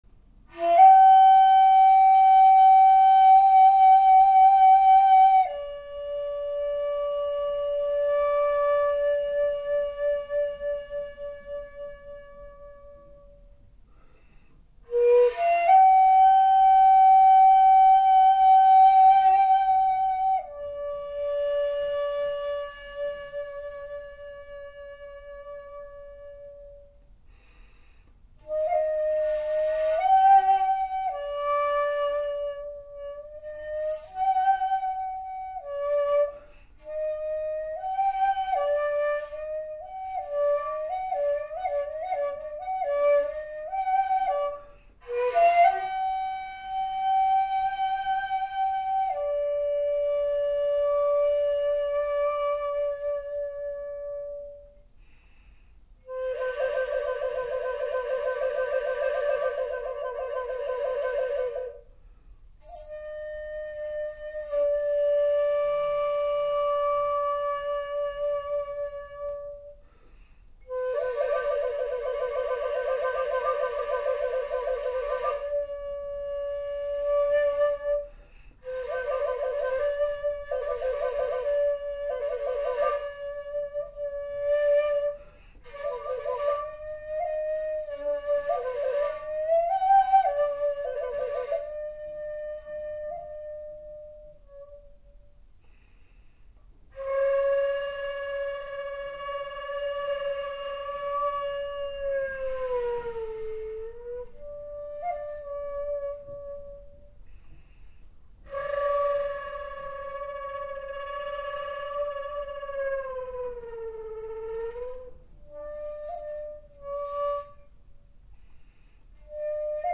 新年を祝って琴古流本曲「鶴の巣ごもり」を地無し管で吹いてみました。